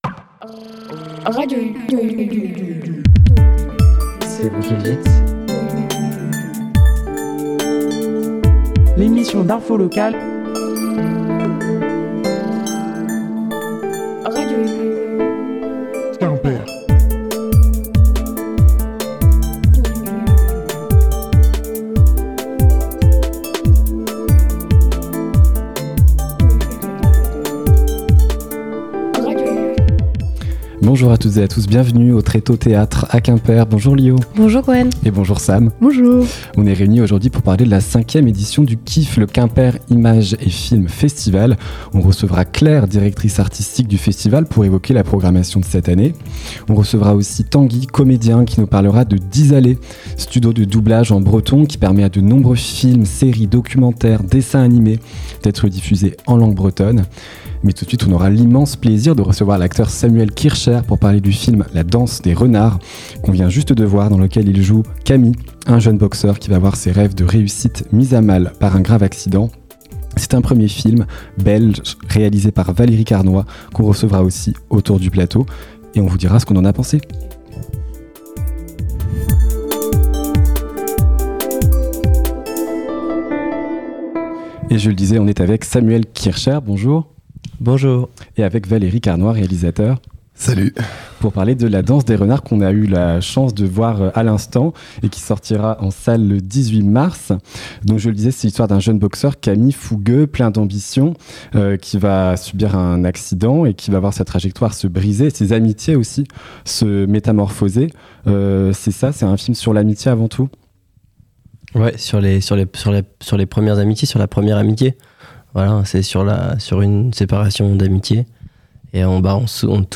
Pour ce second plateau de Radio U au Qiff, le Quimper Images & Films Festival, nous avons eu l’immense plaisir de recevoir l’acteur Samuel Kircher, pour parler du film La Danse des Renards, dans lequel il joue Camille, un jeune boxer qui va voir ses rêves de réussite mis à mal par un grave accident.
radio_u_au_qiff_2026_plateau_du_vendredi.mp3